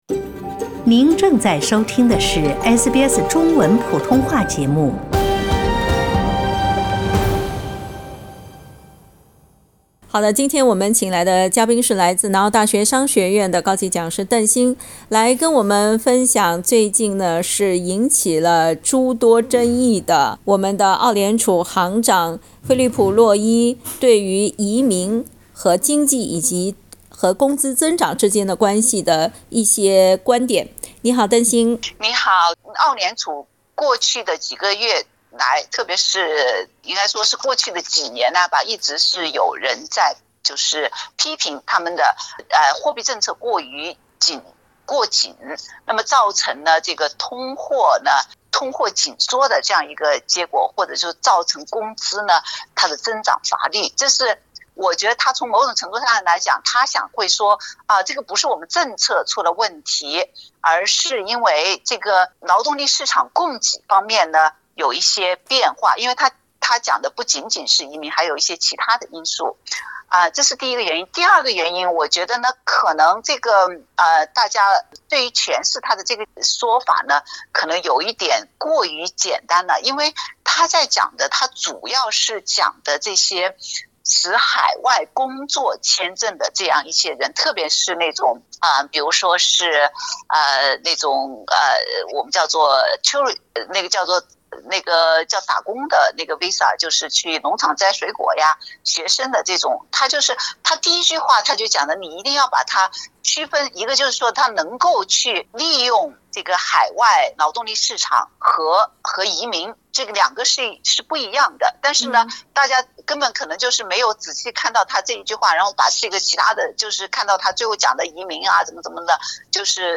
（本節目為嘉賓觀點，不代表本台立場，請聽寀訪） 澳大利亞人必鬚與他人保持至少1.5米的社交距離，請查看您所在州或領地的最新社交限制措施。